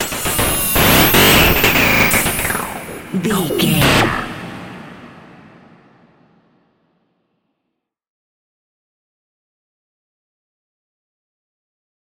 In-crescendo
Thriller
Aeolian/Minor
scary
tension
ominous
dark
suspense
eerie
strings
synth
keyboards
ambience
pads
eletronic